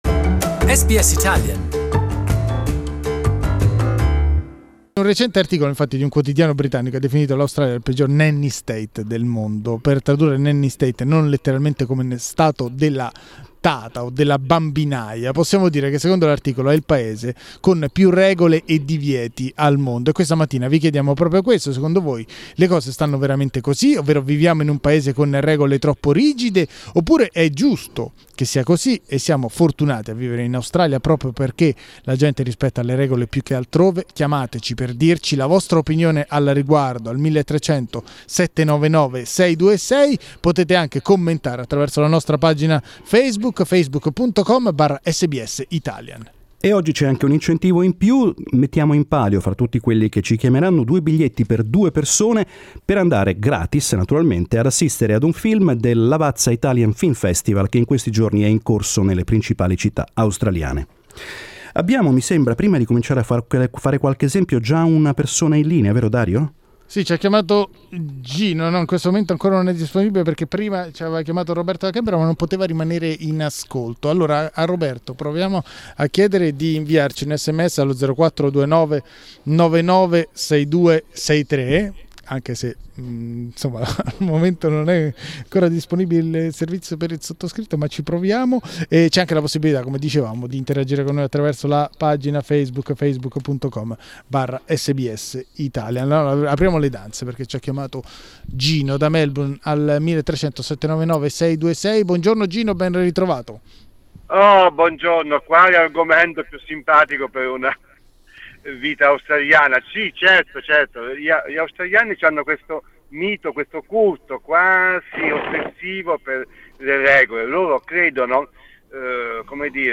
The countless rules enforced in Australia make us safer, improve our social well-being and protect vulnerable people ... or do they interfere with our fundamental individual freedoms? That's the question we asked our listeners this morning. Here's what they answered.